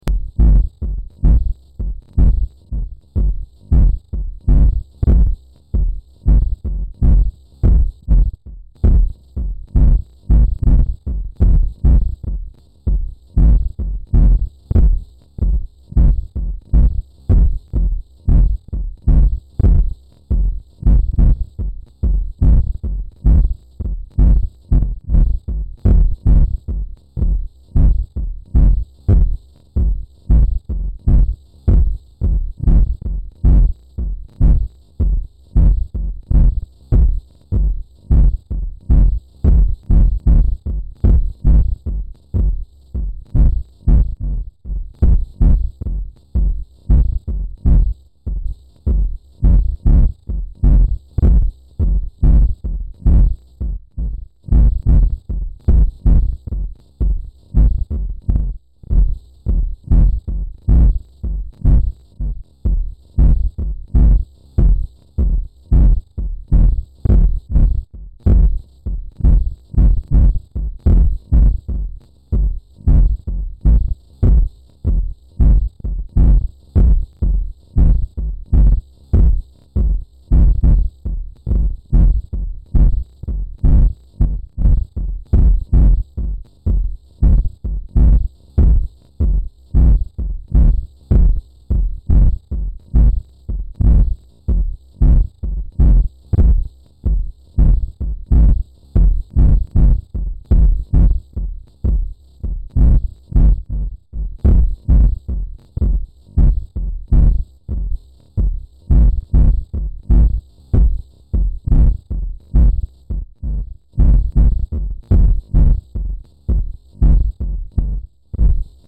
El sonido de este reto corresponde a un rottweiler, macho, de 42 kg, tiene 7 años y estaba en perfectas condiciones físicas, con un peso corporal correcto, condición corporal 5/9, piel y pelo normales y carácter y comportamiento normal.